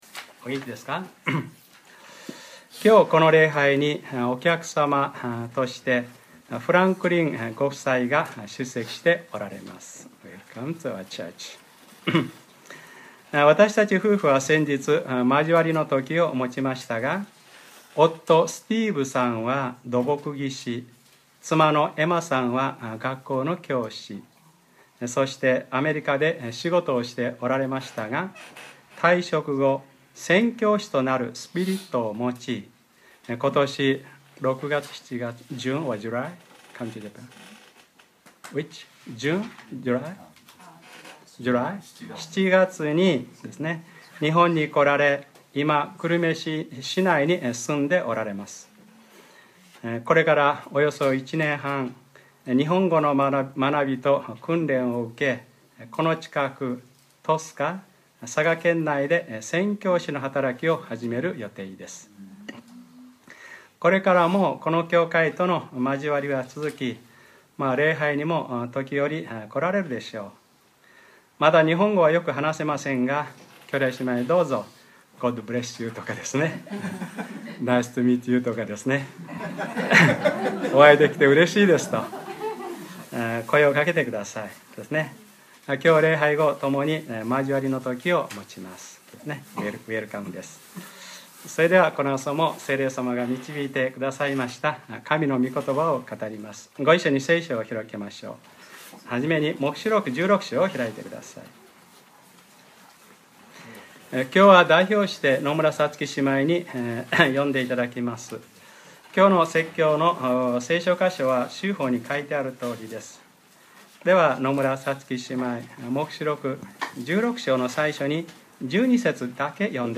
2013年11月24日（日）礼拝説教 『黙示録ｰ２５：神の大いなる日の戦い』 | クライストチャーチ久留米教会